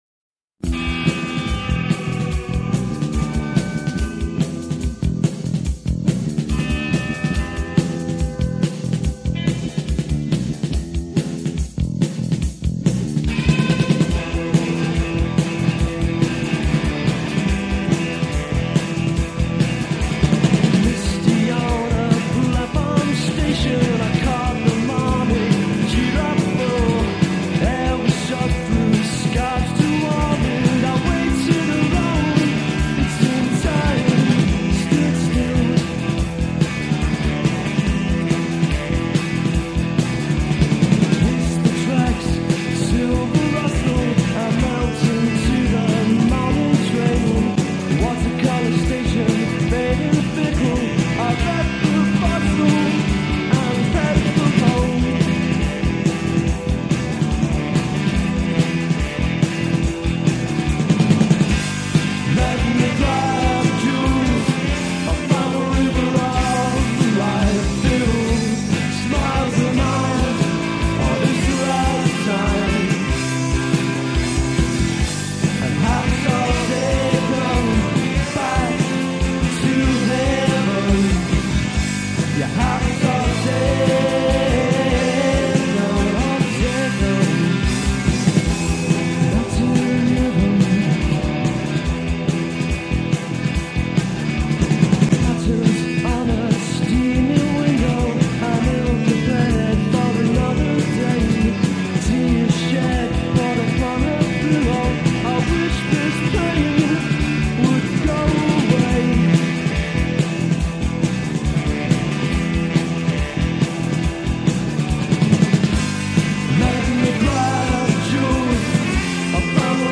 They sound very 80s and also very fresh to this day.
has more influence of post-punk.